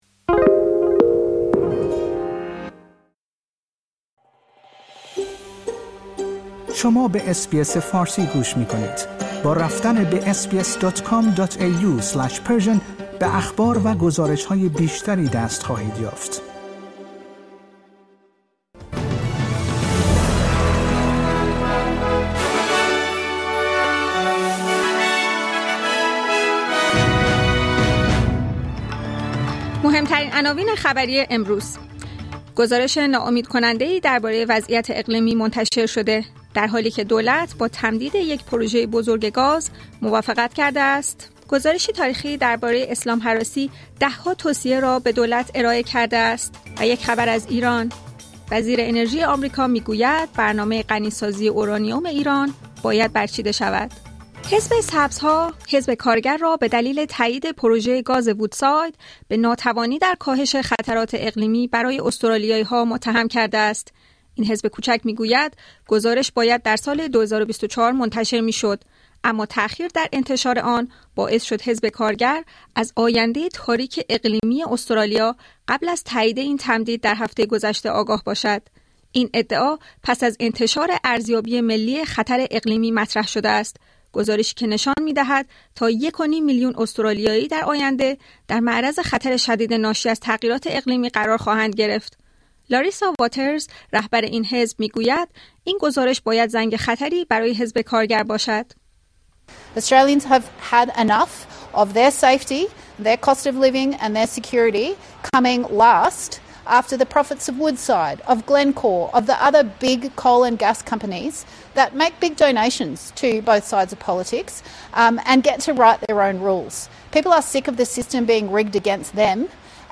در این پادکست خبری مهمترین اخبار هفته منتهی به سه‌شنبه ۱۶ سپتامبر ارائه شده است.